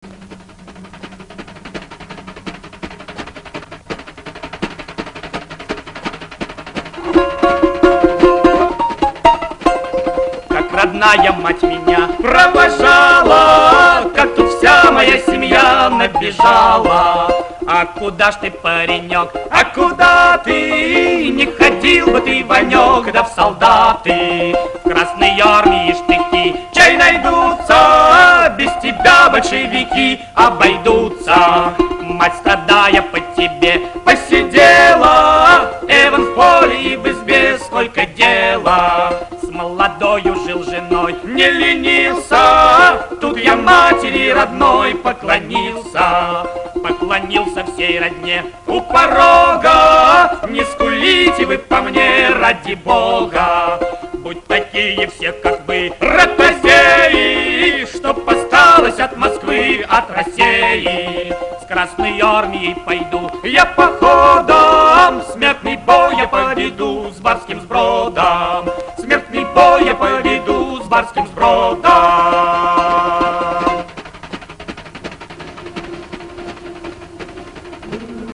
Исполняет: Два Солисты